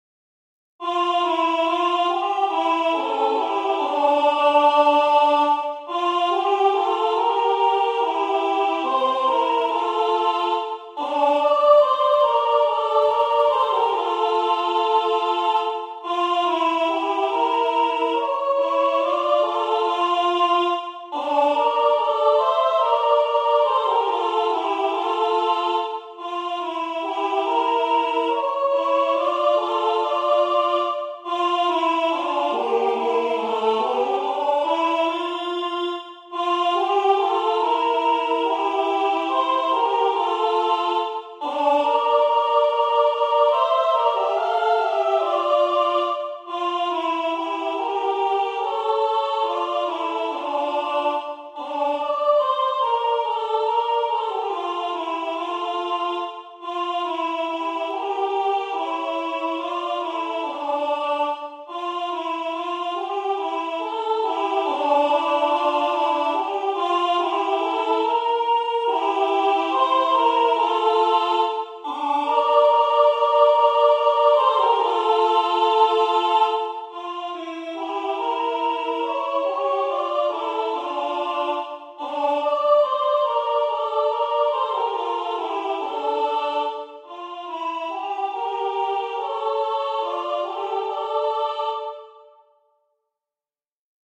Ноты вокальная партитура.